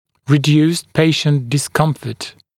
[rɪ’djuːst ‘peɪʃnt dɪs’kʌmfət][ри’дйу:ст ‘пэйшнт дис’камфэт]уменьшение дискомфорта пациента